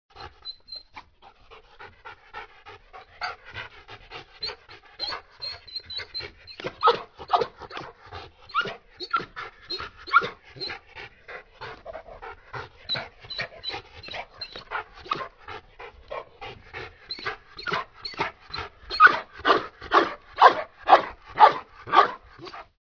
جلوه های صوتی
دانلود صدای نفس نفس زدن سگ و صدای سوت سگ از ساعد نیوز با لینک مستقیم و کیفیت بالا